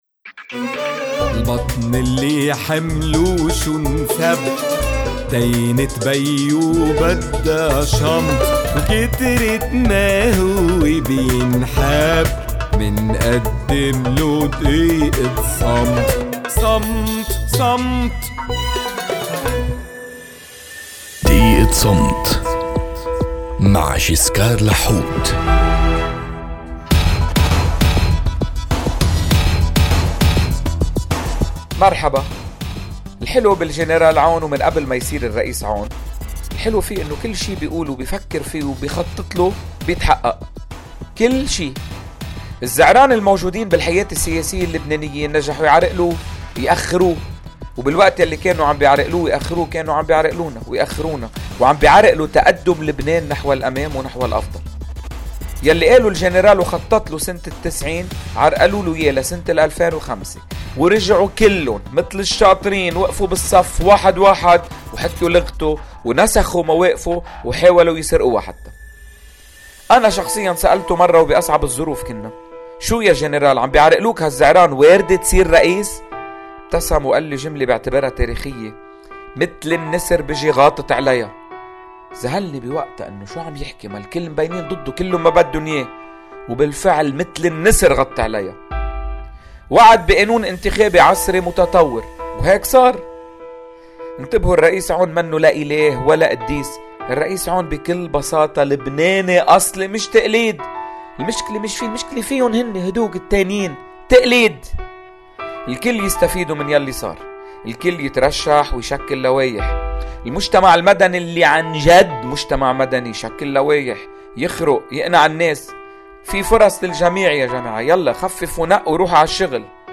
على إذاعة “صوت المدى” (92.5 FM)